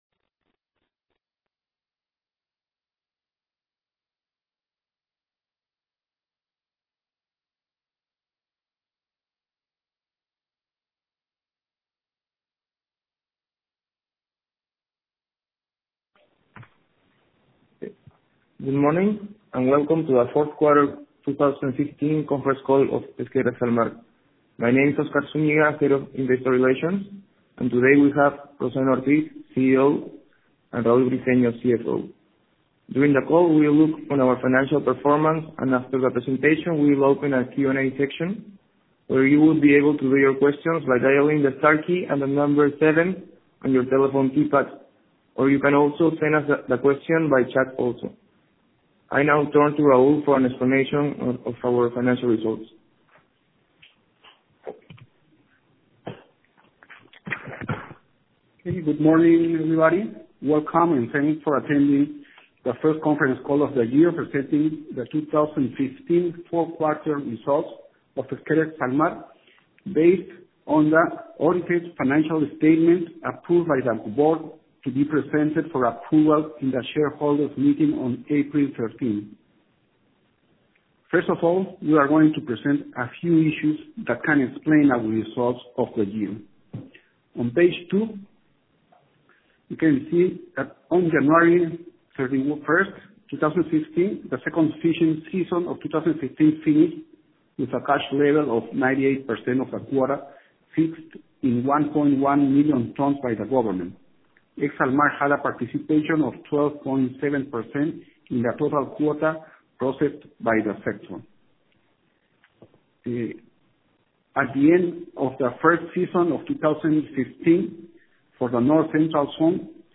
Navegación de entradas Publicado en Conference Call 3Q15